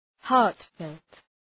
Προφορά
{‘hɑ:rt,felt}